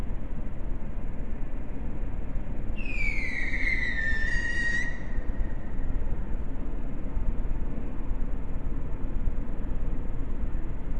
2020 WILTON ENVIRONMENTAL NOISE
LISTEN Flare instability suggested 01:10 LISTEN  25/6/20 23:50 Strange high pitched noise -probably local.